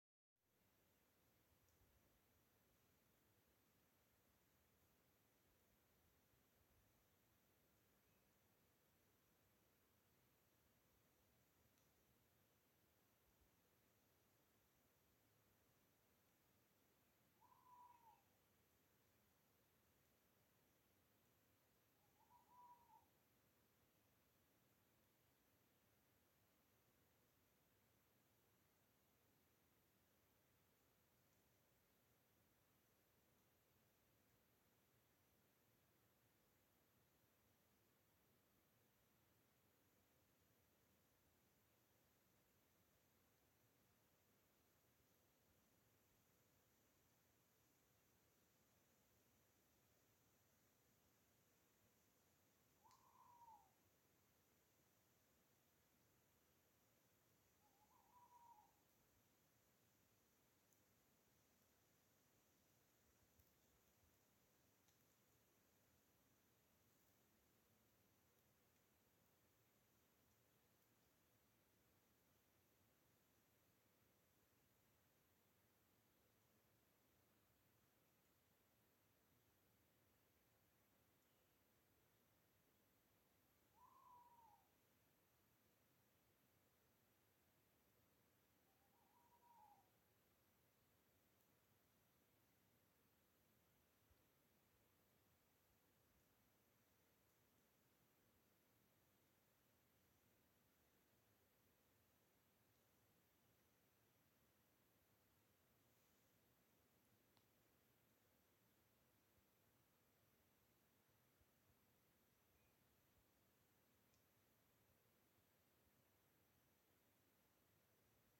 серая неясыть, Strix aluco
Administratīvā teritorijaKocēnu novads
СтатусСлышен голос, крики